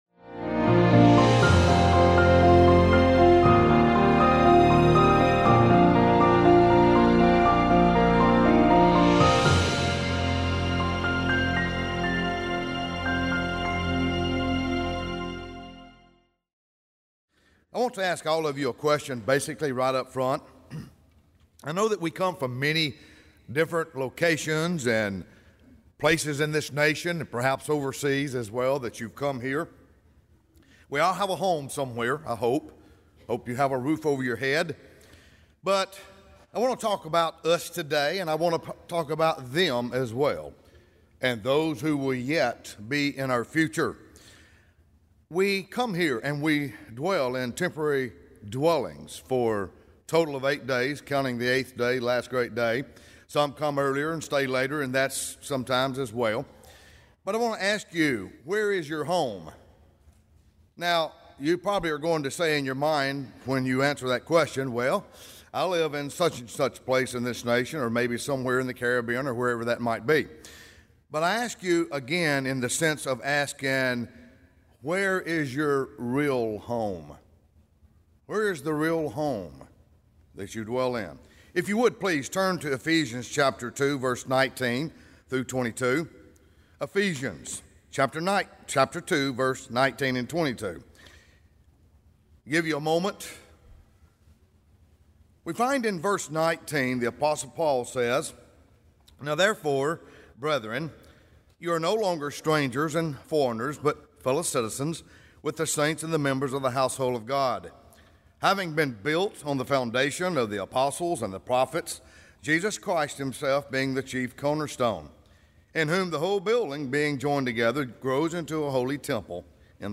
This sermon was given at the Panama City Beach, Florida 2019 and Celebrating the Feast of Tabernacles Online 2020 Feast sites.